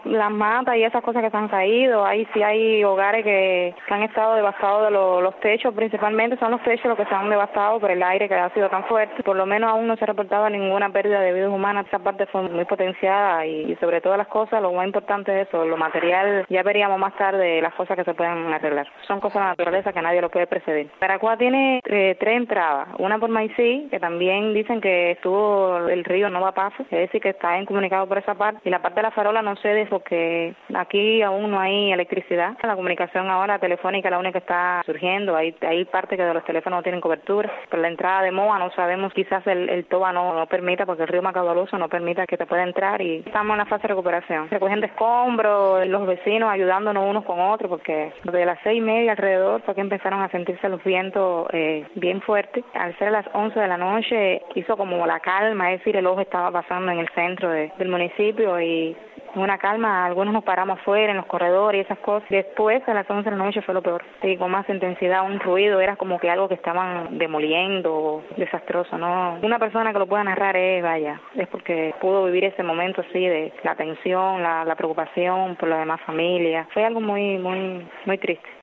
Residentes de Baracoa narraron a Radio Martí parte de lo que vivieron durante el paso del huracán Matthew, que dejó destrucción y tristeza en el extremo oriental de la isla.